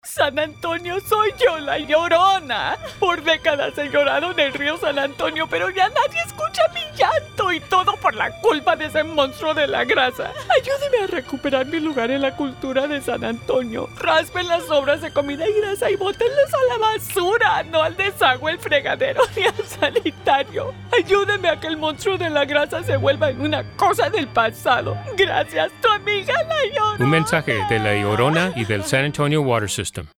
San Antonio Water System - Radio